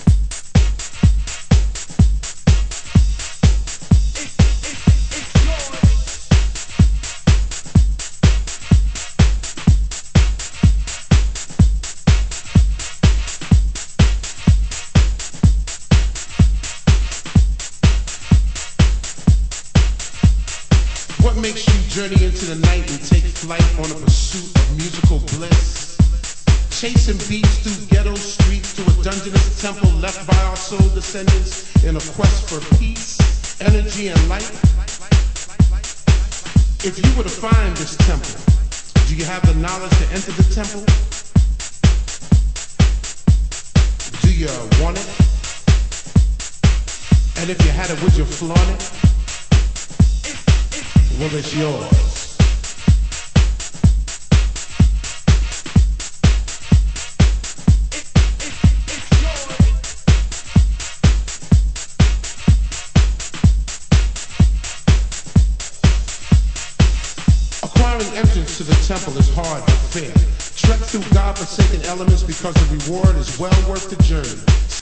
盤質：盤面良好ですが少しチリパチノイズ有　　ジャケ：少しスレ有